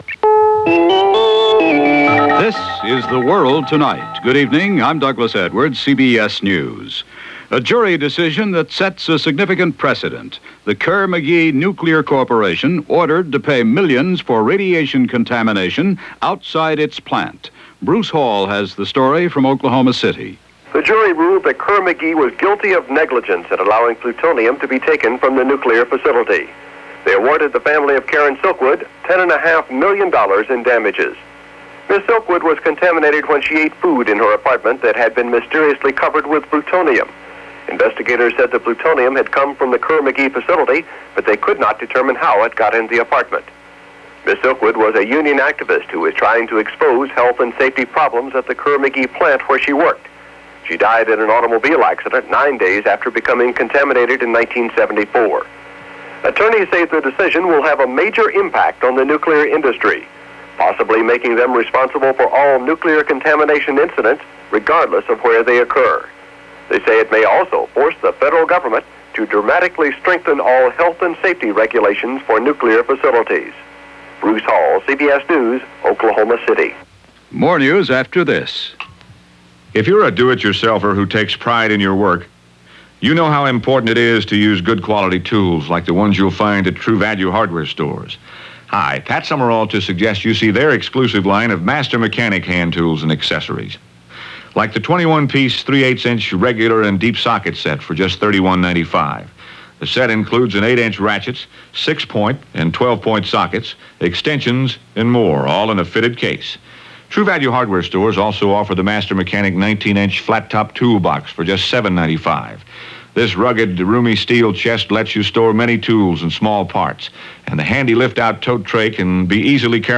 May 18, 1979 - A Vindication: Karen Silkwood - Equal Opportunity Gas Shortages - Raids In Lebanon - news for this day in 1979 from The World Tonight.